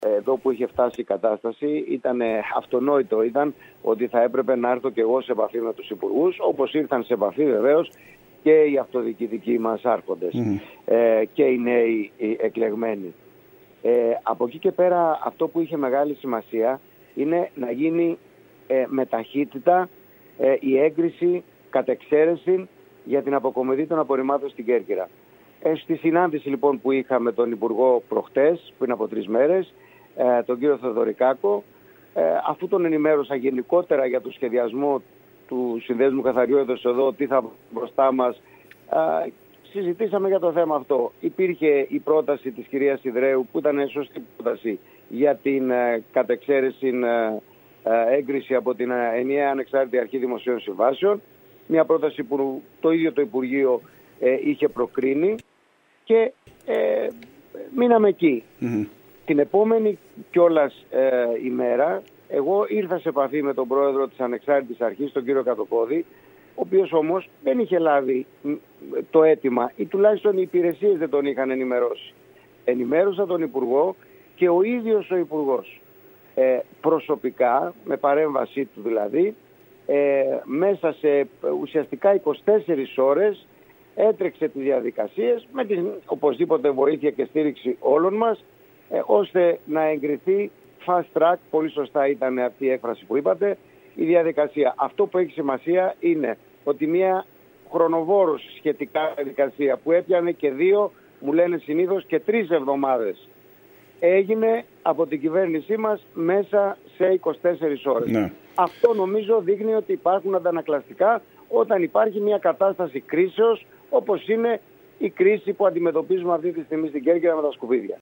Μιλώντας στην ΕΡΤ Κέρκυρας, ο βουλευτής της ΝΔ Στέφανος Γκικάς, τόνισε την αμεσότητα της αντίδρασης της κυβέρνησης η οποία σε επίπεδο υπουργού, υπέγραψε τις απαραίτητες διαδικασίες εντός 24 ωρών ώστε οι όγκοι των απορριμμάτων να απομακρυνθούν από το κάδρο της καλοκαιρινής Κέρκυρας